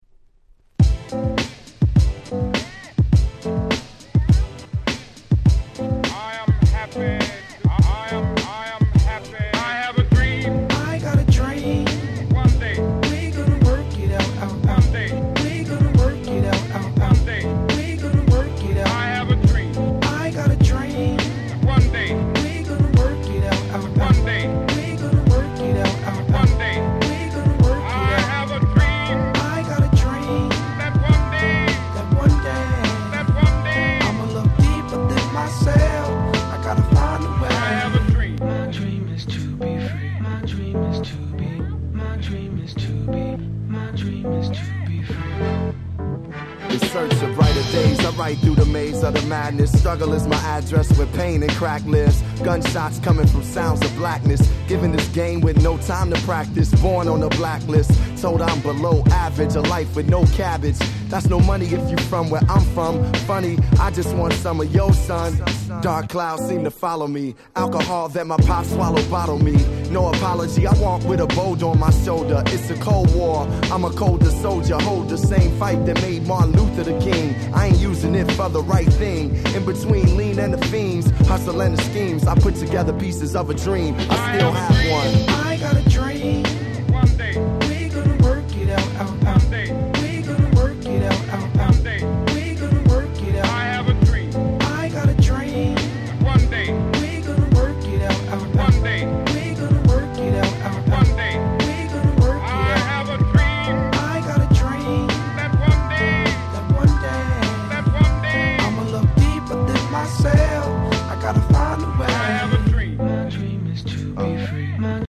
06' Nice Hip Hop !!